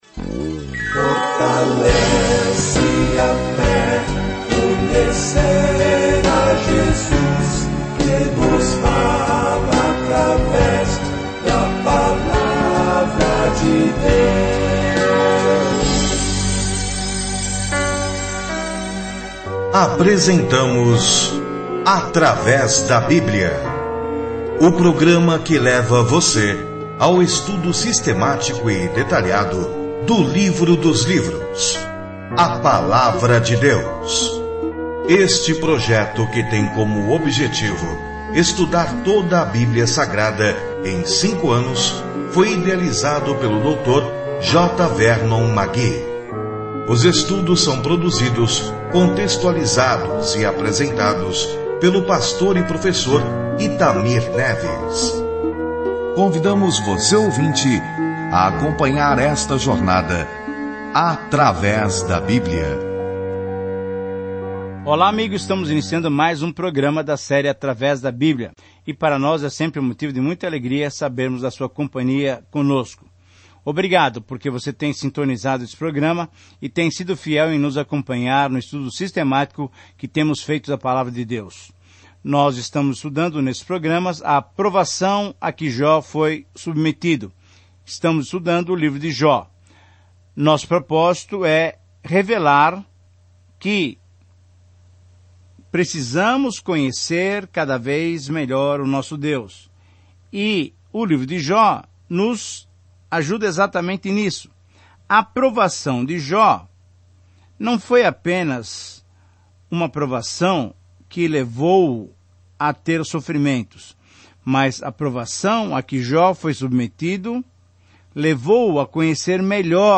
As Escrituras Jó 3:1-2-26 Dia 2 Começar esse Plano Dia 4 Sobre este Plano Neste drama do céu e da terra, encontramos Jó, um homem bom, a quem Deus permitiu que Satanás atacasse; todo mundo tem tantas perguntas sobre por que coisas ruins acontecem. Viaje diariamente por Jó enquanto ouve o estudo em áudio e lê versículos selecionados da palavra de Deus.